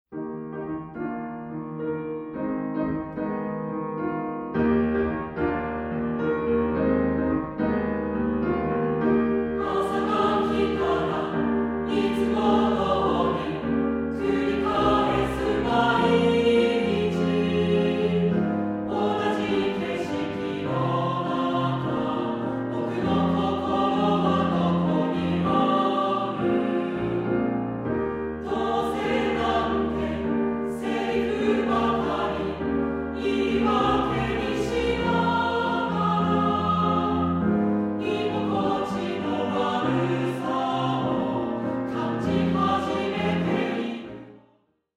混声3部合唱／伴奏：ピアノ